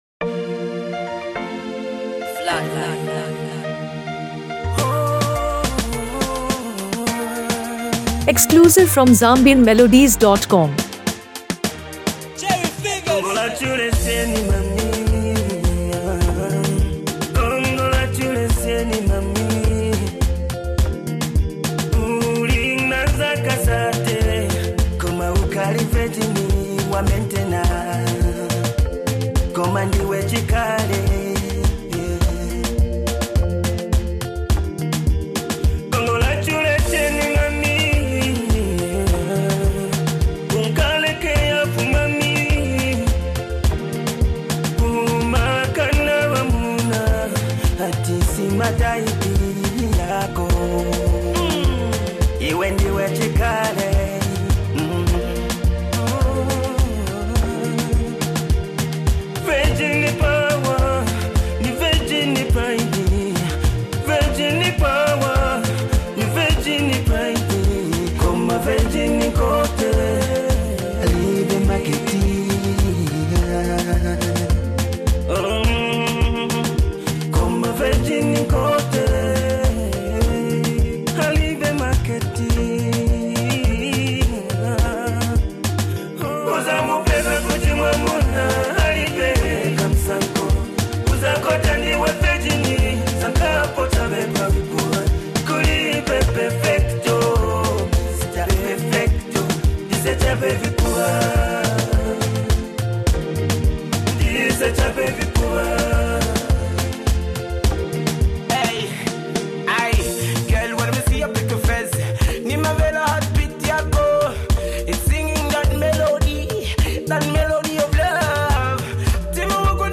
Afro-fusion with a touch of Zambian contemporary sound